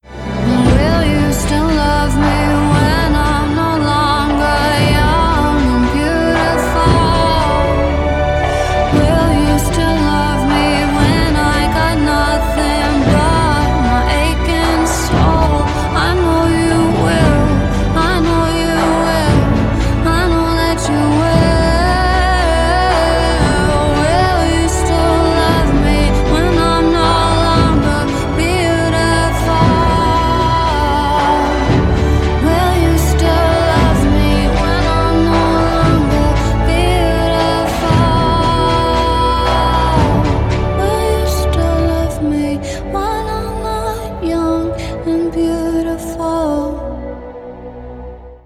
романтические